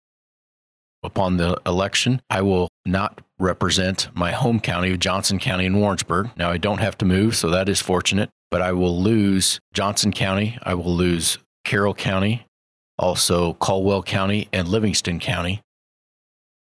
1. Senator Hoskins says changes are coming to the 21st Senatorial District.